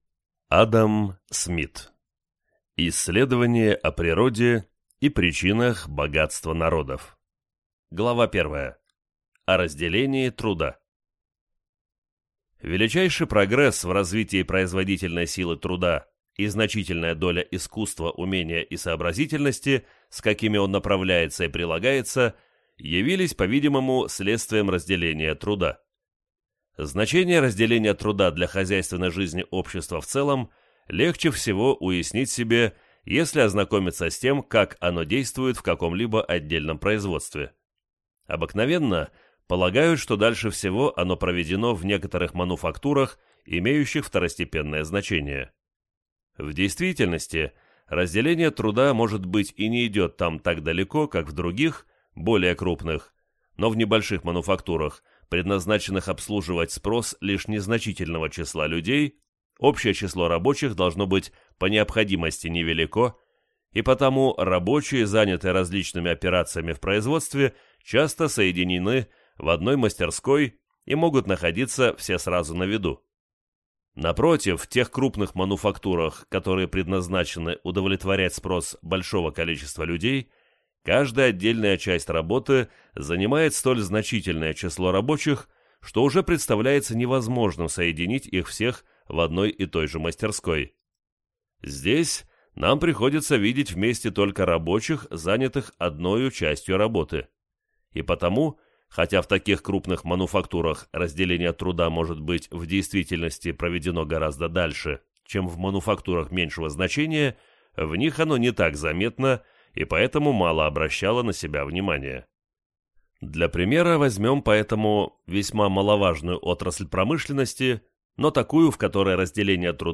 Аудиокнига Исследование о природе и причинах богатства народов | Библиотека аудиокниг